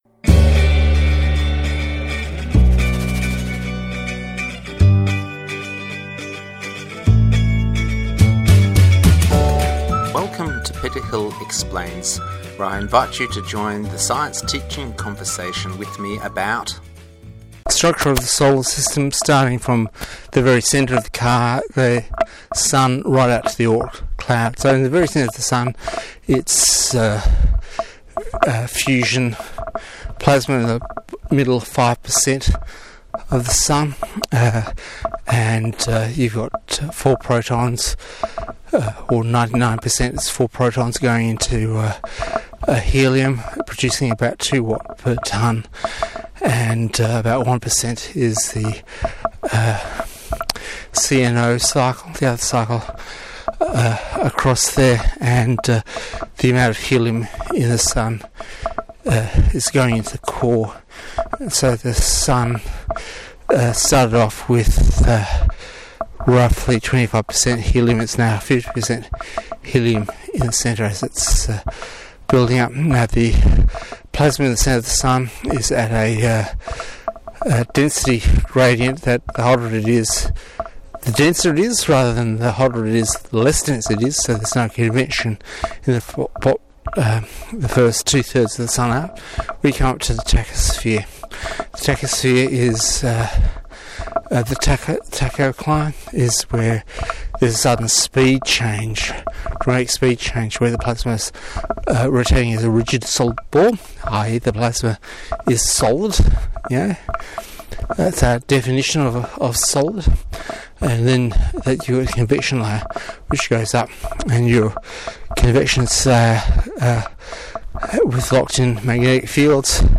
Solar System Brain Dump The walk home from the gorge, so its time to let the facts jostle around and come out.